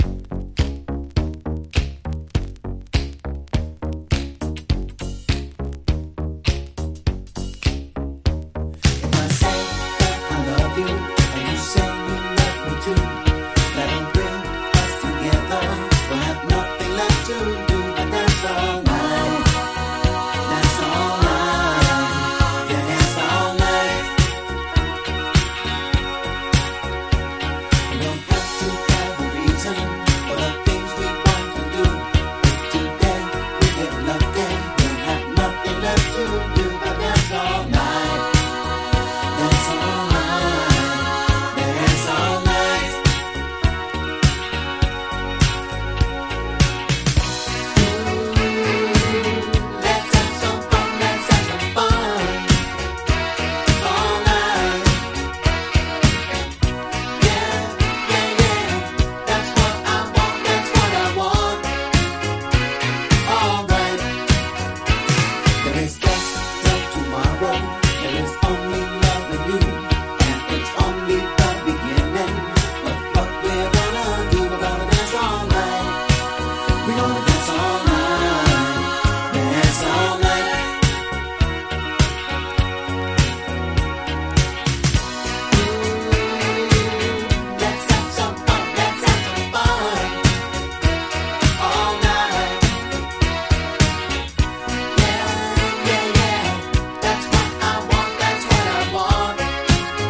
ブラジリアンなアレンジと泣きメロが溶け合う